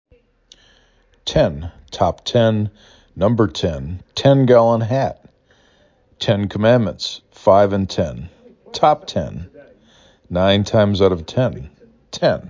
3 Letters, 1 Syllable
3 Phonemes
t e n
t en